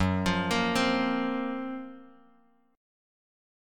F#M#11 chord